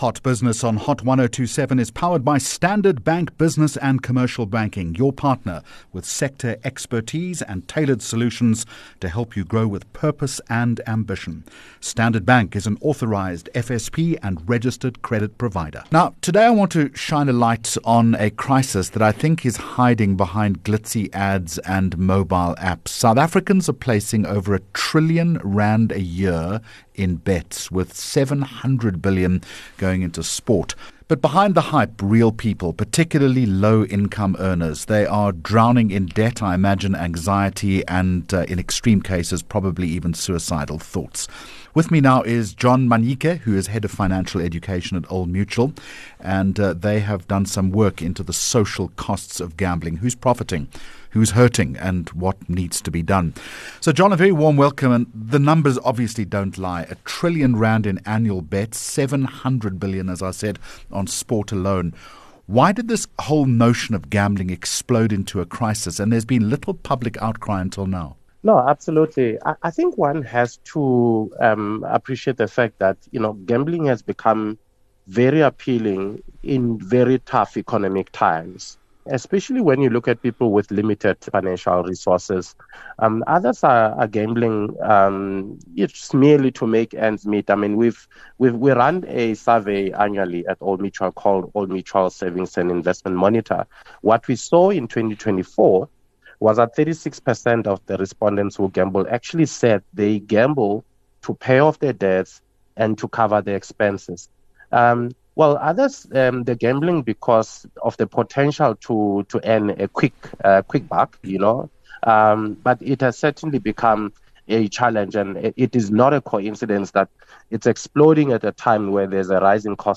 10 Jun Hot Business Interview